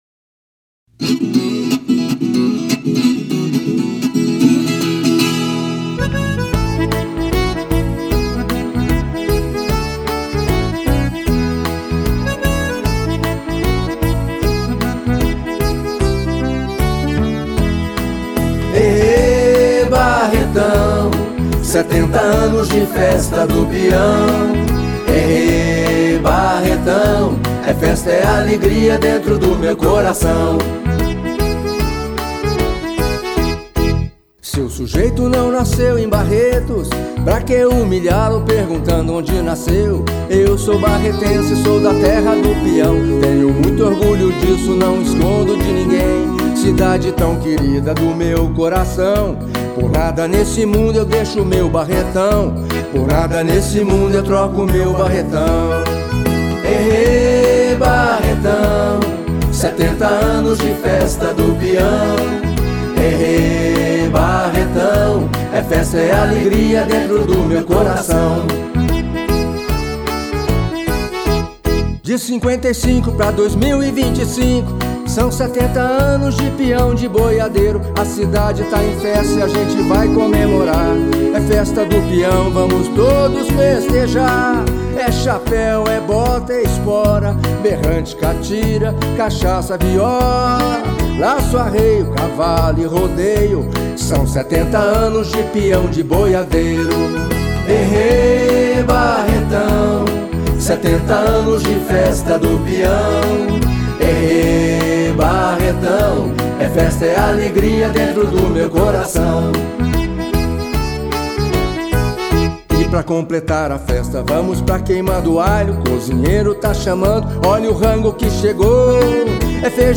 backing vocal
acordeon
teclados
berrante
viola